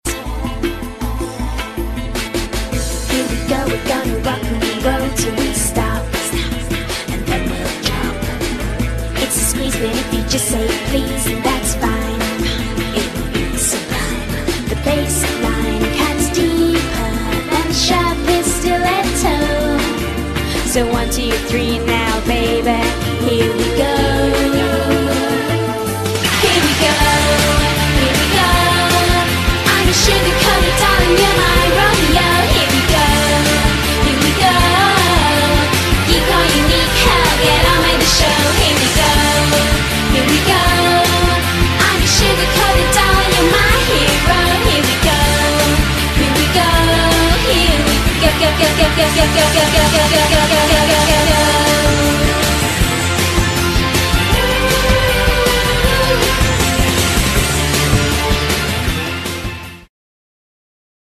Real Life Totally Spies Transformation, Gadgets sound effects free download